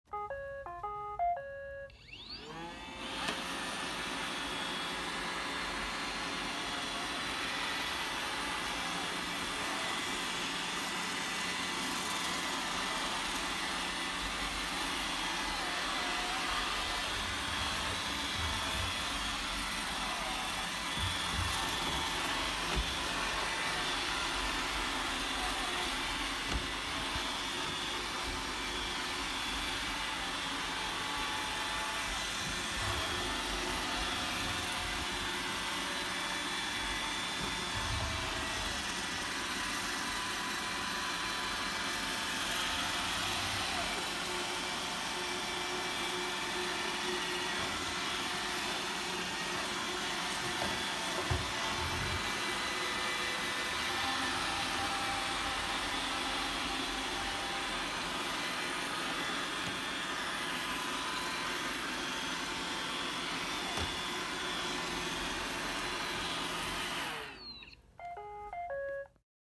Звуки робота пылесоса
Этот монотонный гул идеально подходит для использования в качестве фонового белого шума, который помогает сконцентрироваться, расслабиться или замаскировать посторонние шумы.